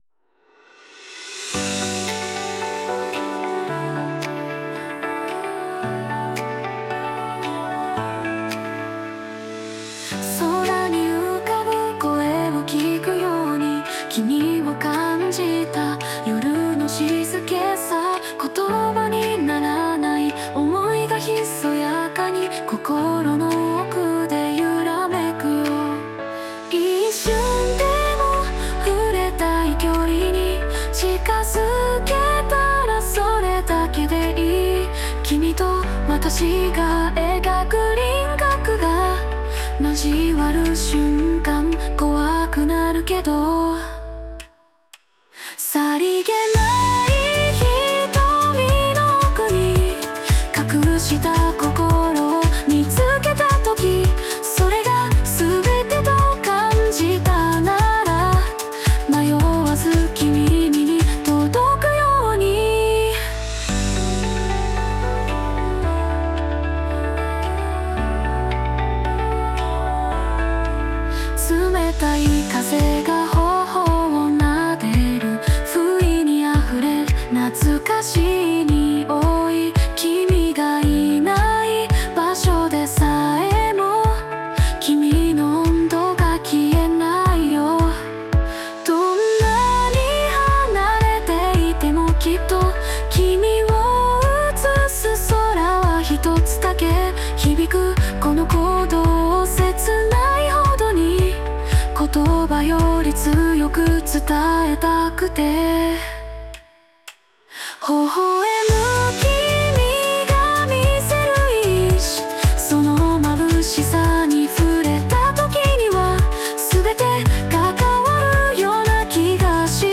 邦楽女性ボーカル著作権フリーBGM ボーカル
女性ボーカル（邦楽・日本語）曲です。